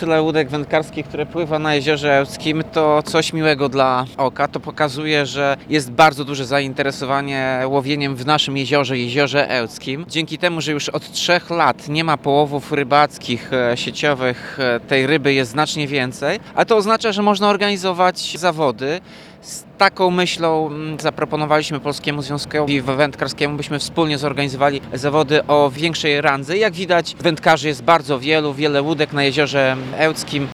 Tomasz Andrukiewicz, prezydent Ełku mówi, że duże zainteresowanie to wynik tego, że już kilka lat w akwenie nie są prowadzone odłowy sieciowe.